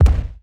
EXPLDsgn_Explosion Impact_01_SFRMS_SCIWPNS.wav